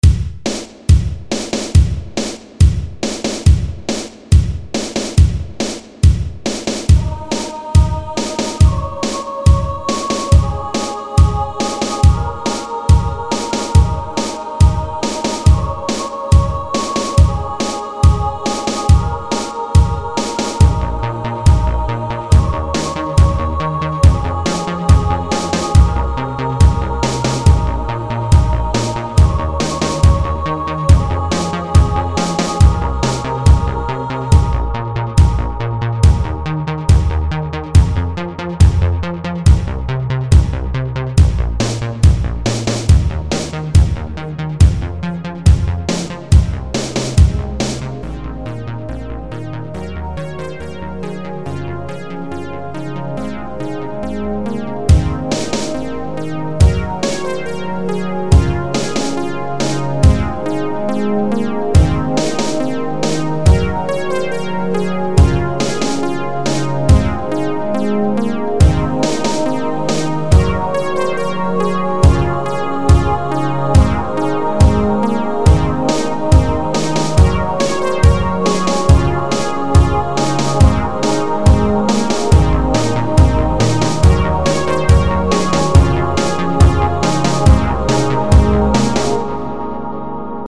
Yeah, I got these awesome new 80's drum samples.